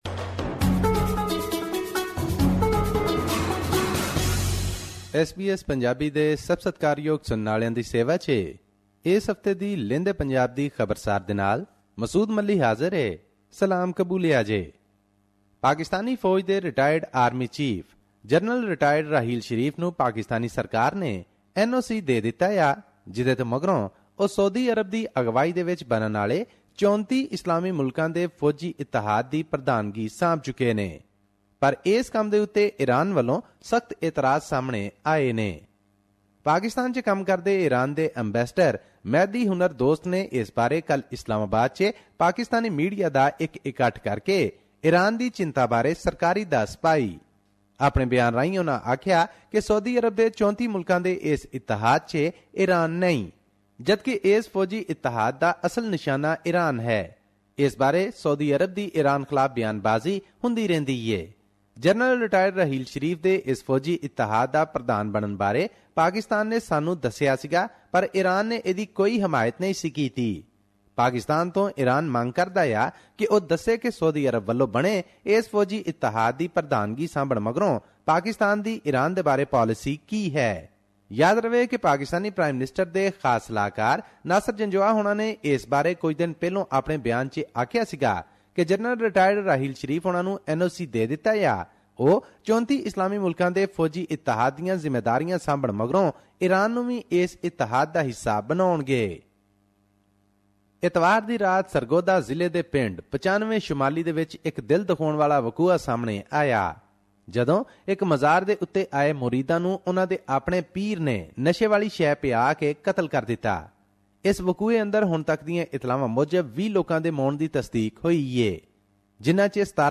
His report was presented on SBS Punjabi program on Monday, April 04, 2017, which touched upon issues of Punjabi and national significance in Pakistan. Here's the podcast in case you missed hearing it on the radio.